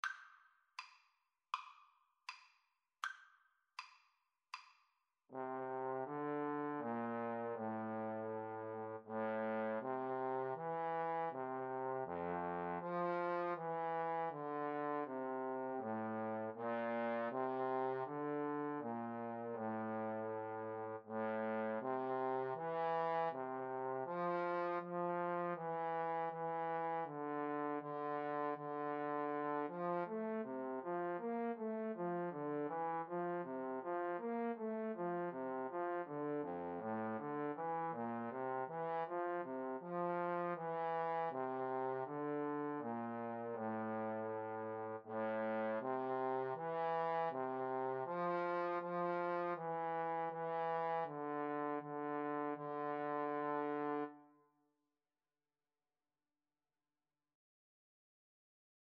4/4 (View more 4/4 Music)
Andante Espressivo = c. 80
Trombone Duet  (View more Intermediate Trombone Duet Music)